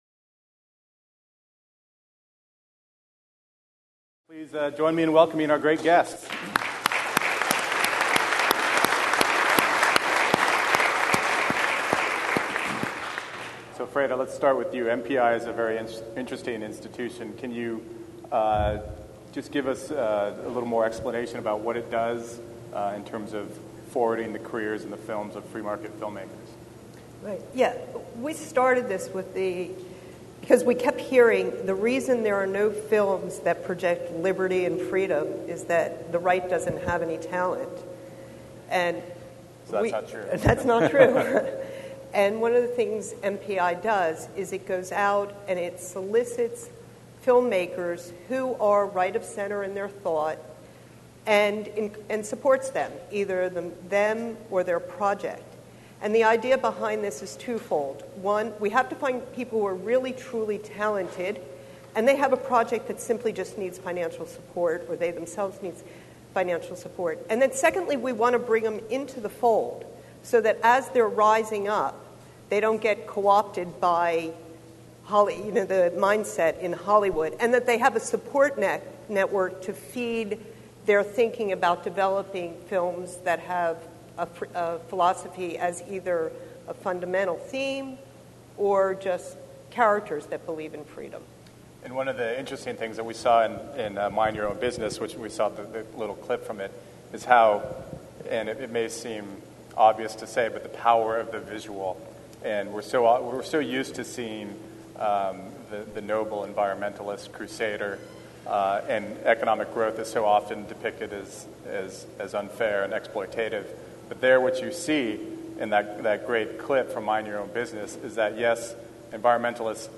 At Reason Goes Hollywood, our 40th anniversary bash held November 14-15, 2008 in Los Angeles
a lively discussion